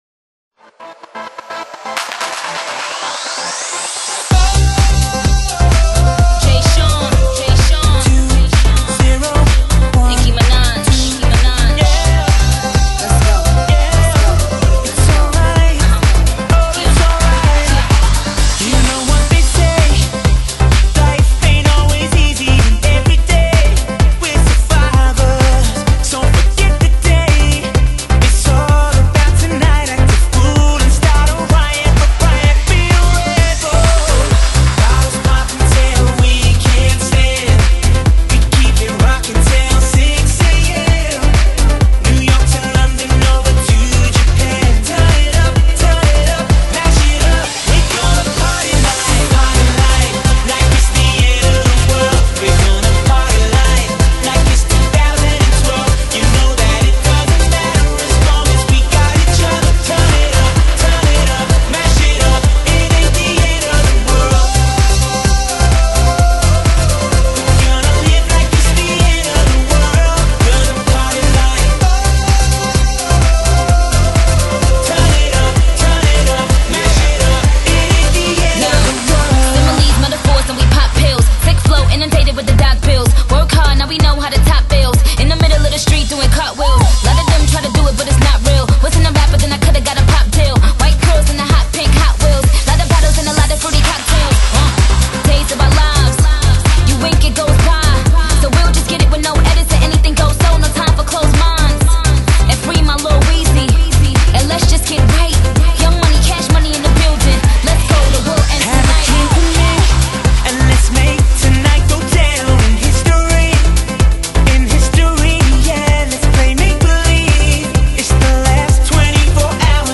19首電音熱舞舞曲 讓大家從2010~搖到2011年 哈~~~~~~~~